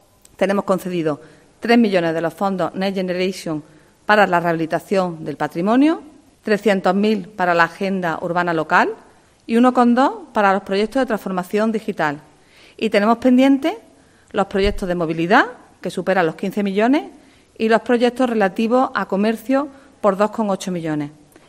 Escucha a Blanca Torrent, Teniente de alcalde de Reactivación Económica